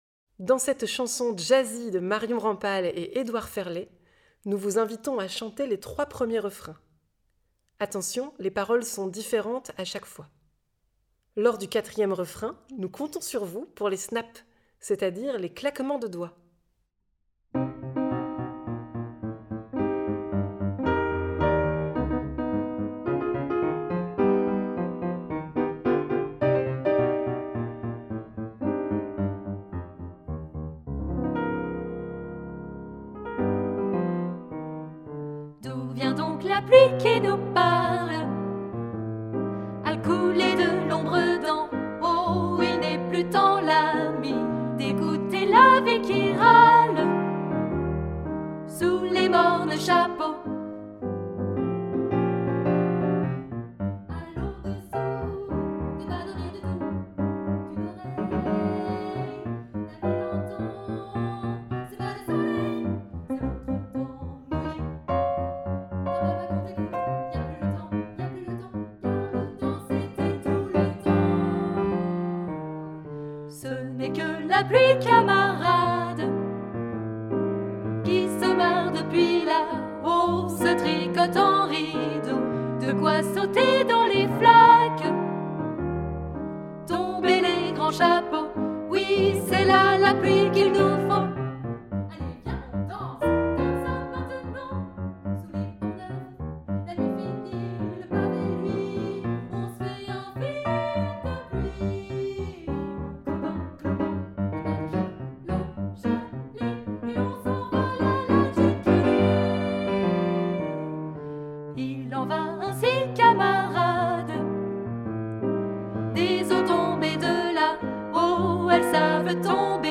Genre :  Chanson
Version tutti choeur participatif